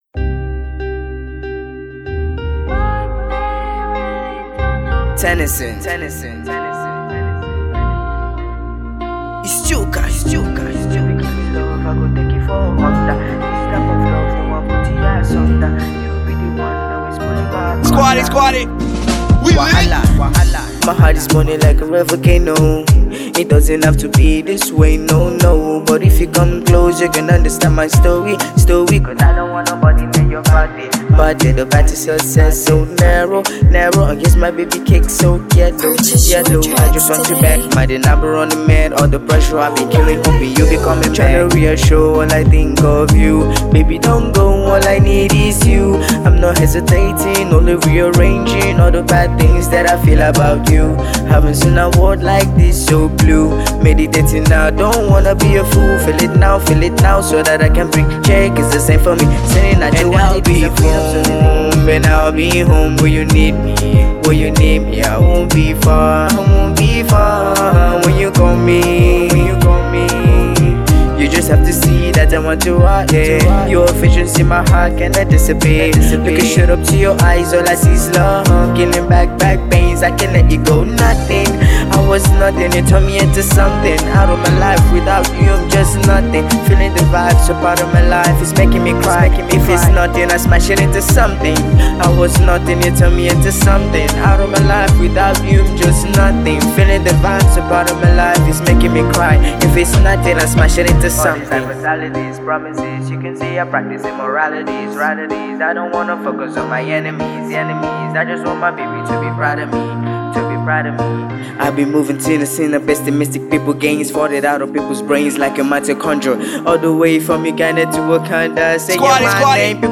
Afro singer